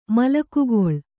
♪ malakugoḷ